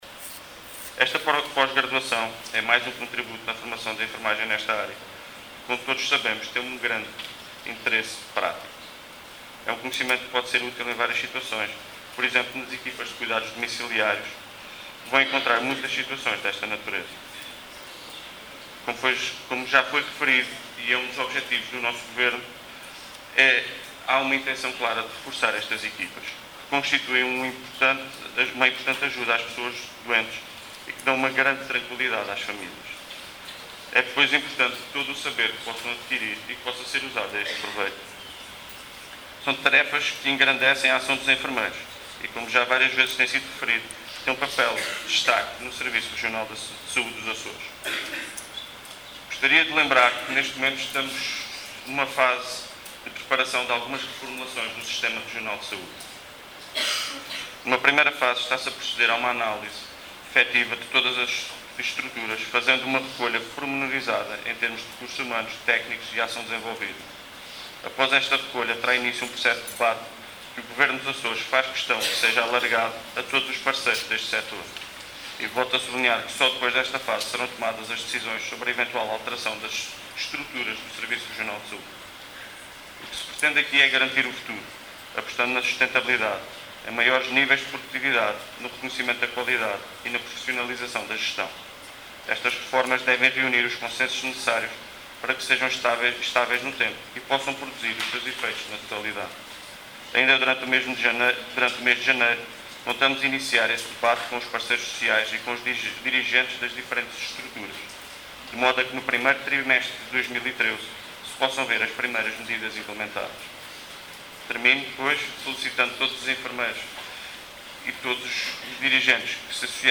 Luís Cabral, que falava na sessão de abertura do I Simpósio de Tratamento de Feridas e Viabilidade Tecidular, frisou que, só depois deste debate alargado, é que "serão tomadas as decisões sobre eventuais alterações das estruturas do Serviço Regional de Saúde”.